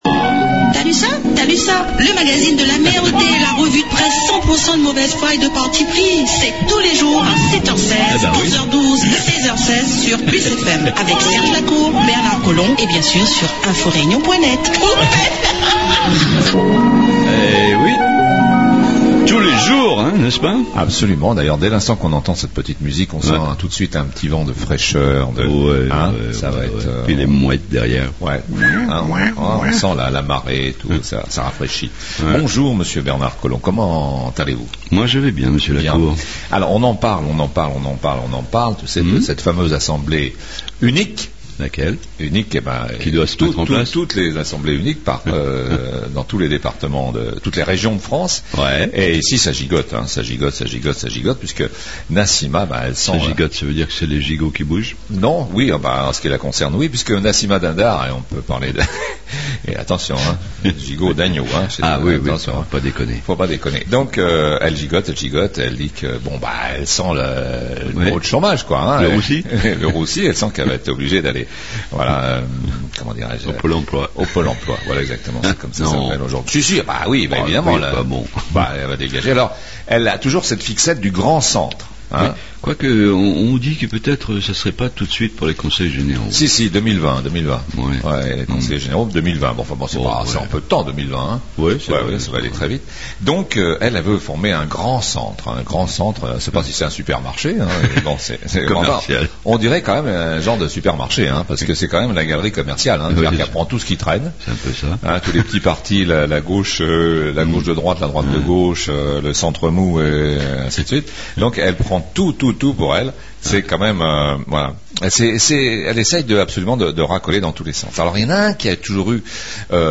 REVUE DE PRESSE Mercredi 4 Juin 2014 Revue de presse locale du jour